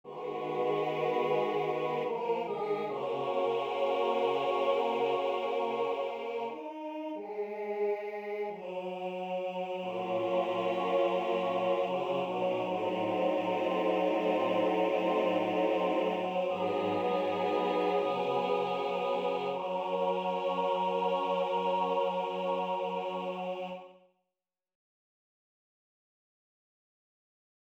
Key written in: F# Major
How many parts: 4
Type: Barbershop
All Parts mix: